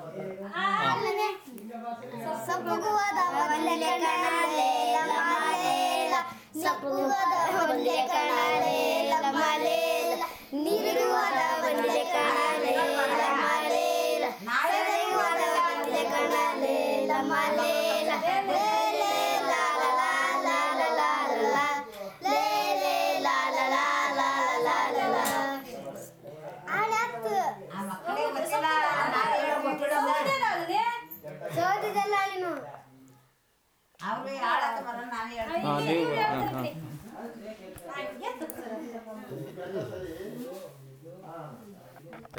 Performance of a folk song about wood collection